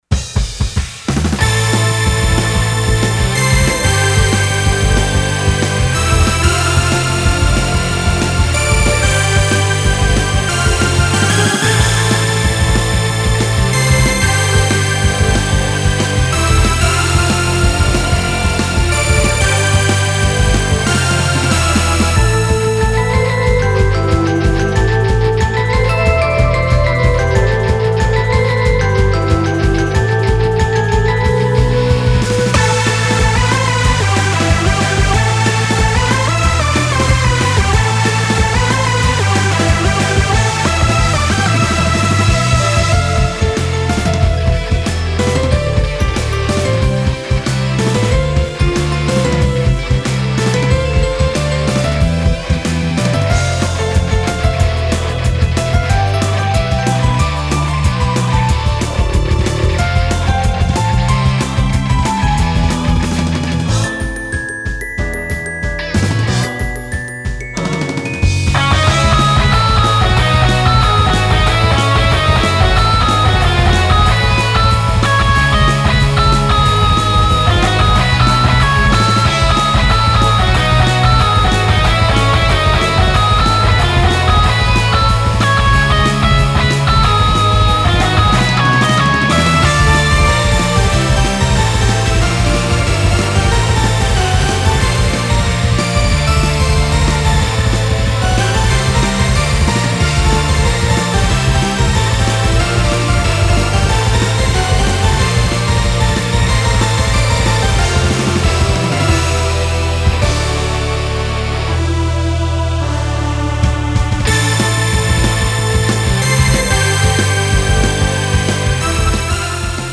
フリーBGM バトル・戦闘 バンドサウンド
フェードアウト版のmp3を、こちらのページにて無料で配布しています。